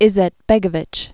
(ĭzĕt-bĕgə-vĭch), Alija 1925-2003.